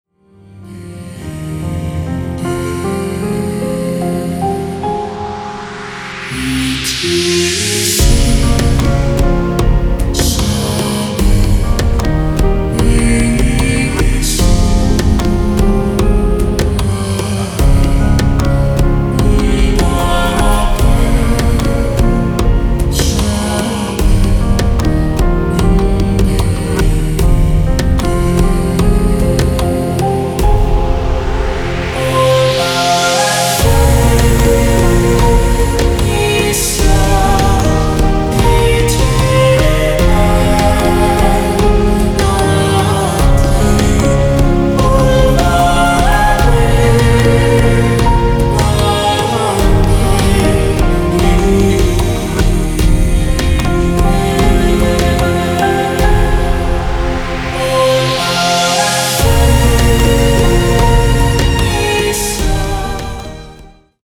cello cinematic cover epic neoclassical piano soundtrack
violin